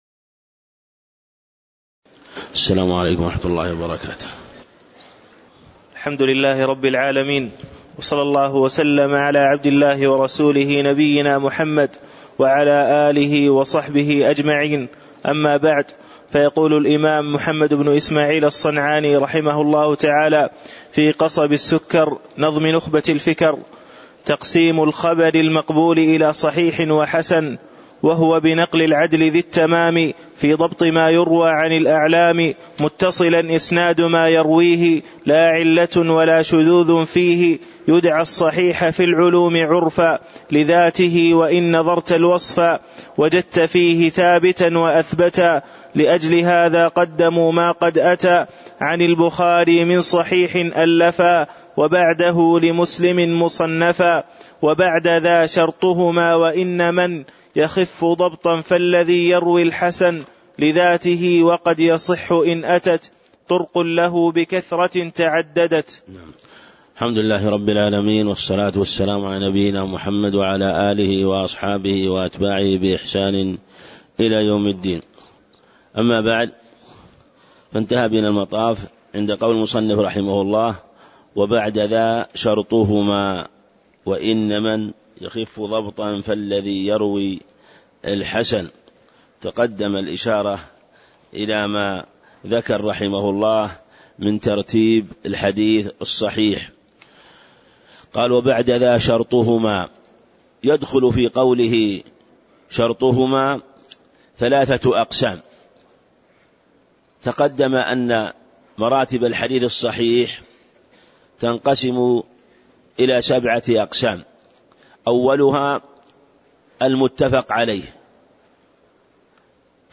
تاريخ النشر ١٤ صفر ١٤٣٨ هـ المكان: المسجد النبوي الشيخ